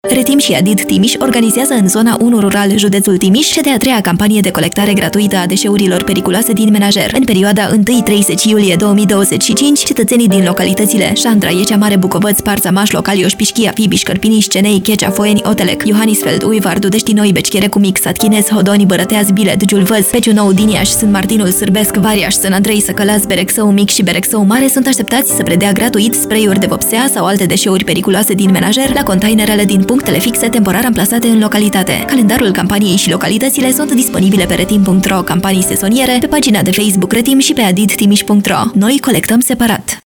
Spot-Radio-Periculoase-din-menajer-Trim.-III-2025-Zona-1.mp3